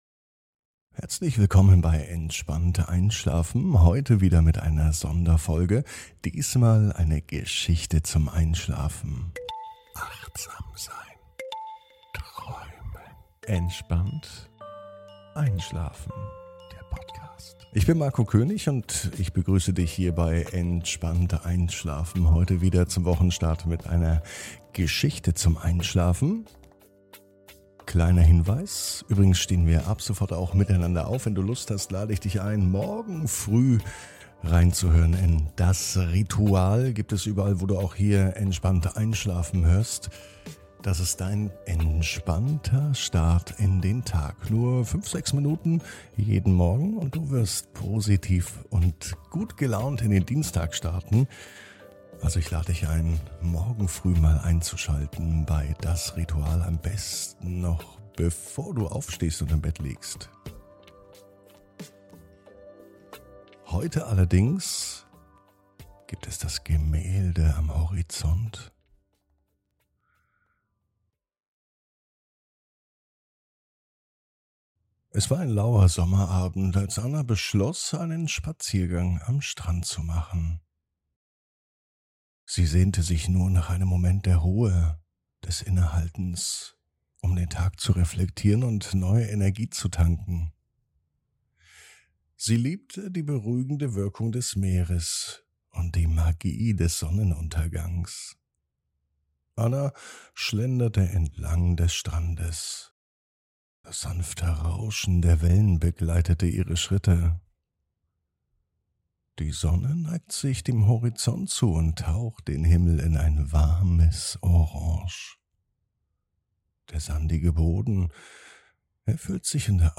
Geschichten zum Einschlafen: "Das Gemälde am Horizont"
Taucht ein in eine Welt der Ruhe und Entspannung mit der Sonderfolge Geschichten zum Einschlafen.